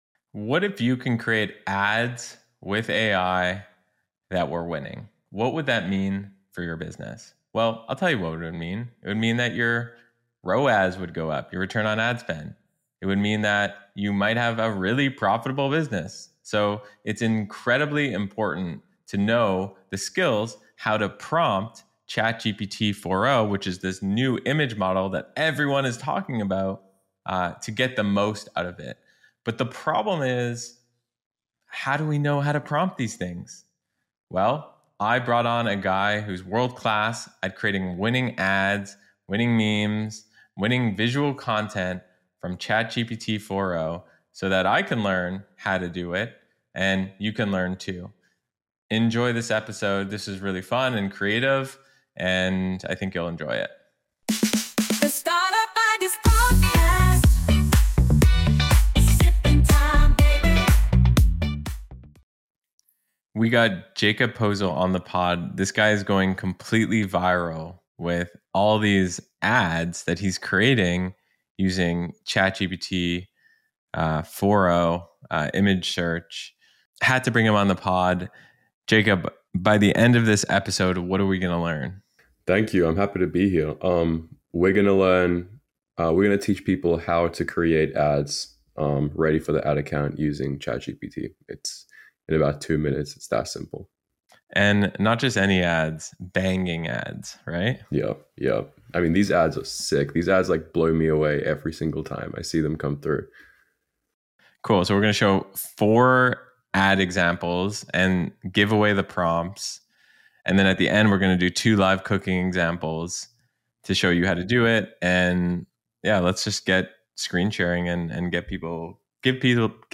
The episode includes four example ads and two live demonstrations, showcasing how marketers can experiment with different audiences and styles at scale to improve conversion rates.